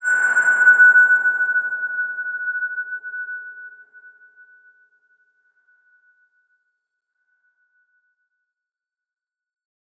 X_BasicBells-F#4-pp.wav